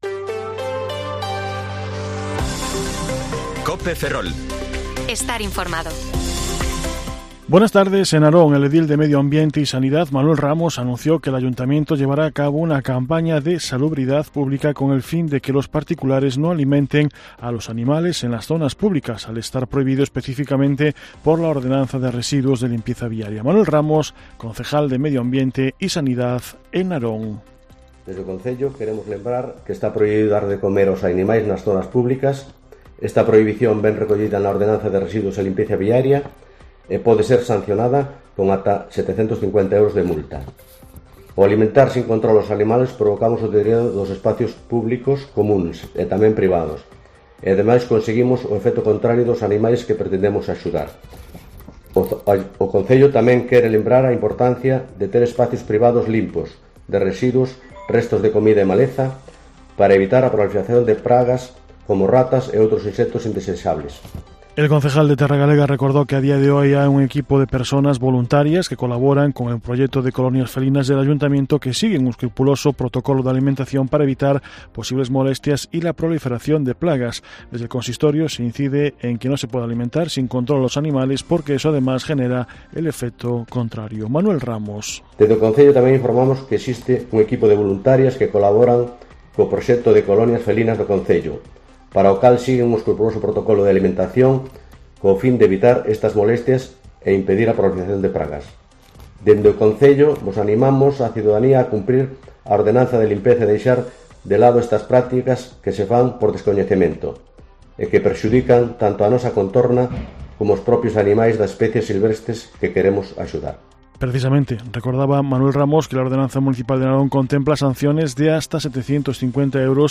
Informativo Mediodía COPE Ferrol 16/8/2023 (De 14,20 a 14,30 horas)